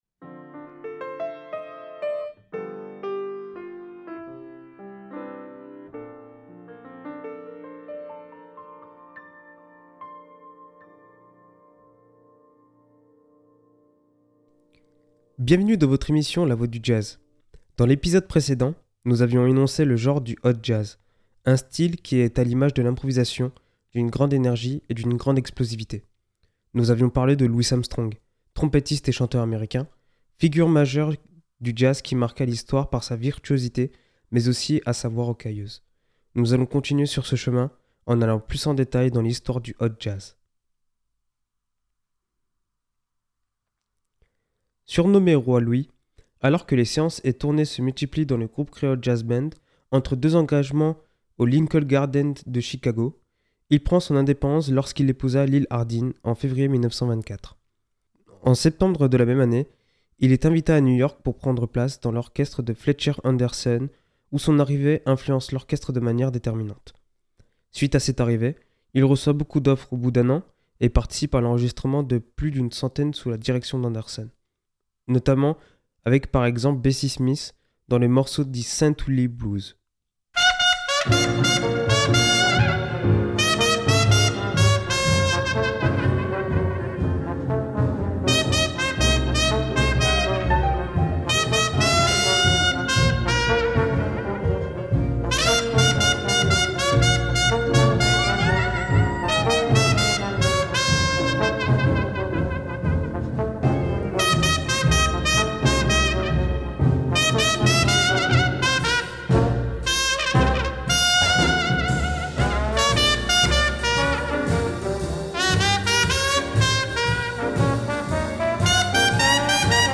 À travers des extraits emblématiques, ce podcast fait revivre l’âme du jazz des années 1920 et la naissance du soliste moderne .